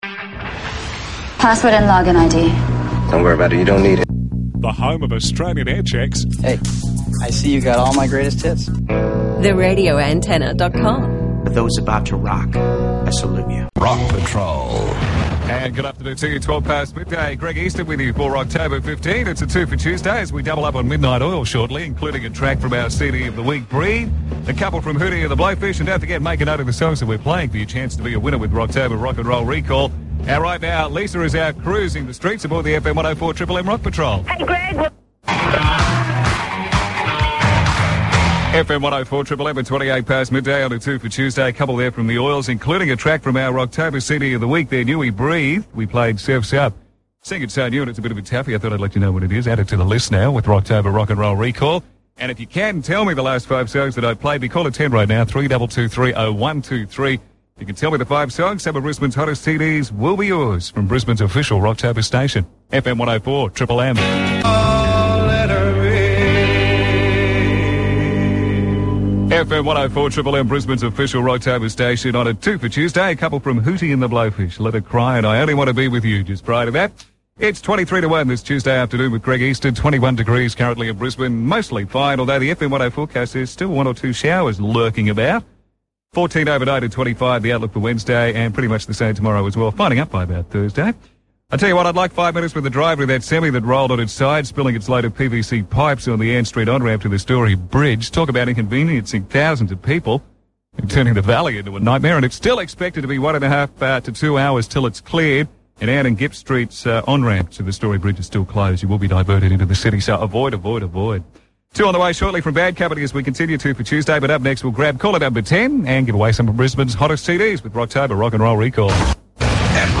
RA Aircheck -FM 104 Triple M